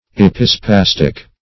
Epispastic \Ep"i*spas"tic\, n. (Med.)